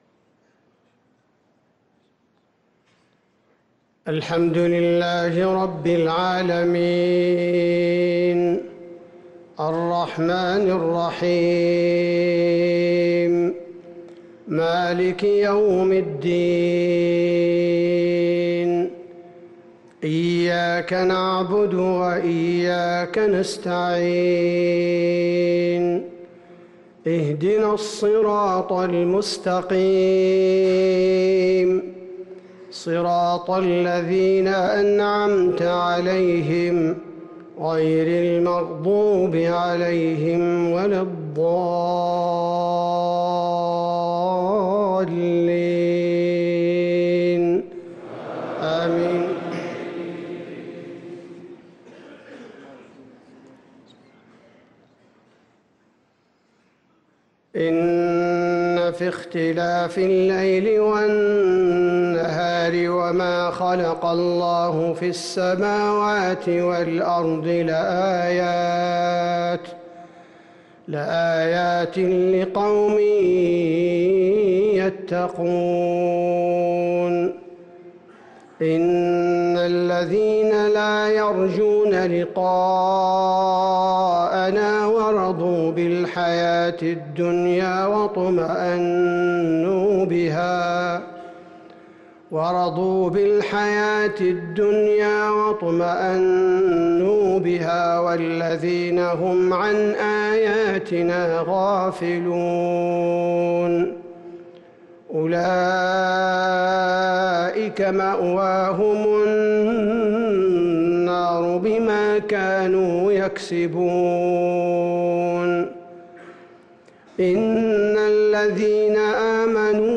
صلاة المغرب للقارئ عبدالباري الثبيتي 24 شوال 1444 هـ
تِلَاوَات الْحَرَمَيْن .